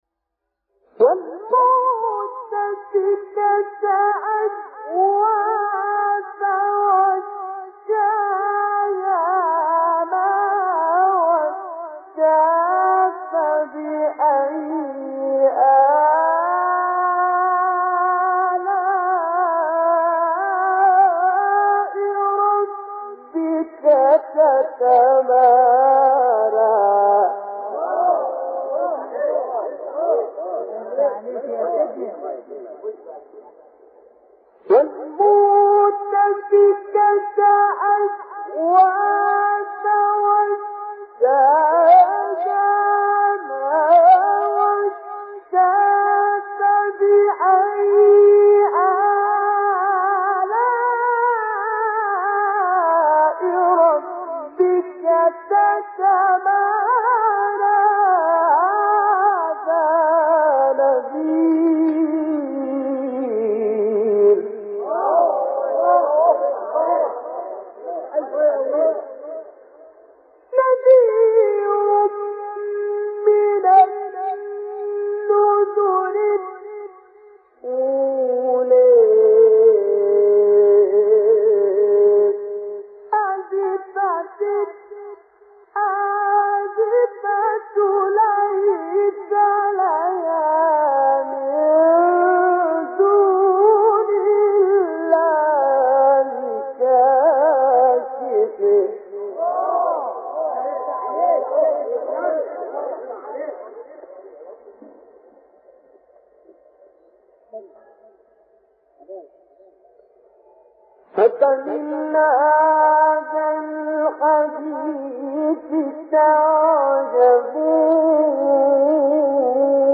تلاوت سوره نجم، قمر 53-62
مقام : مرکب‌خوانی(حجاز * رست)